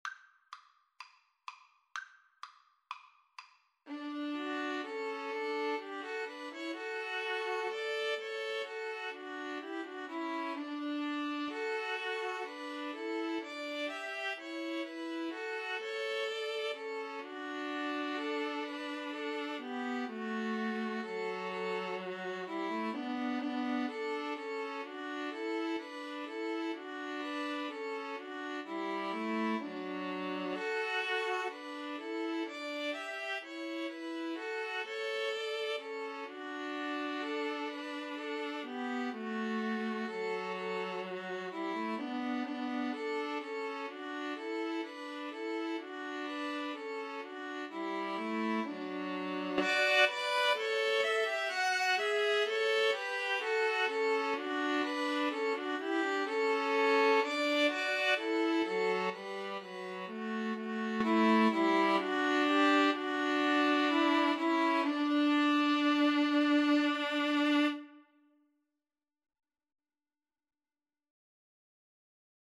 Allegro = 126 (View more music marked Allegro)